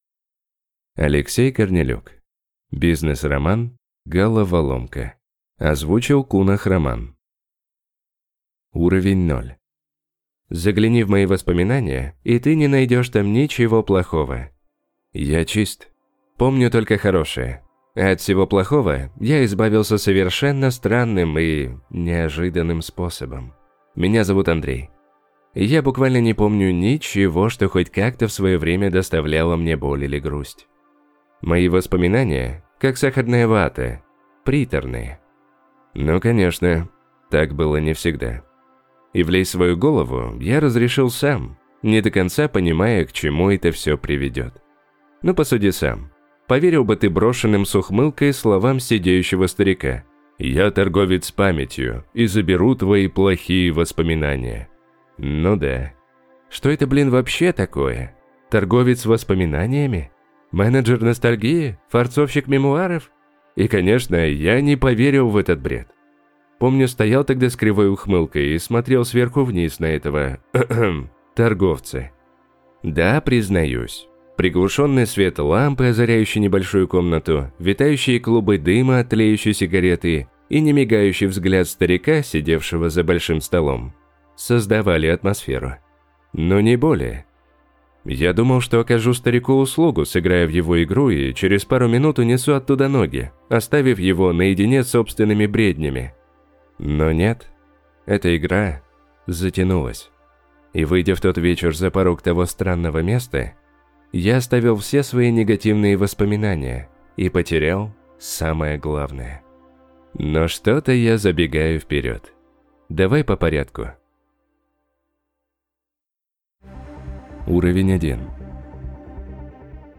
Аудиокнига Головоломка. Бизнес-роман | Библиотека аудиокниг